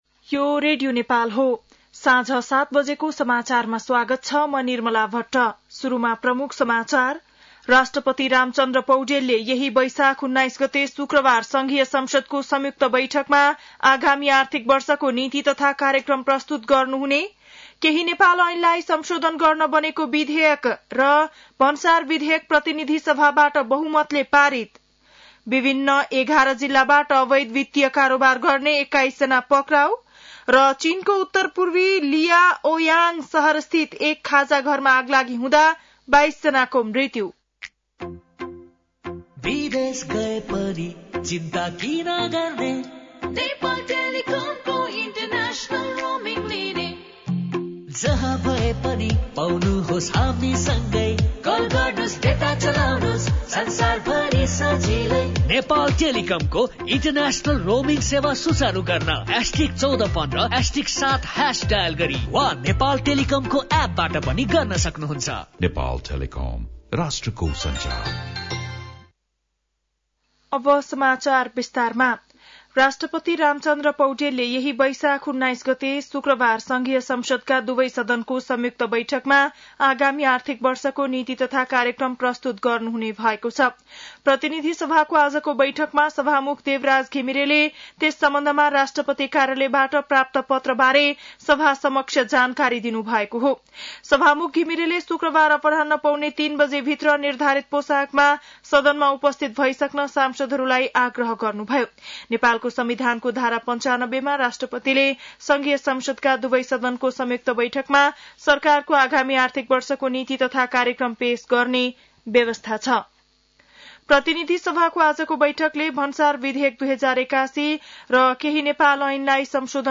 बेलुकी ७ बजेको नेपाली समाचार : १६ वैशाख , २०८२
7-Pm-Nepali-news-1-16.mp3